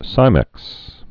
(sīmĕks)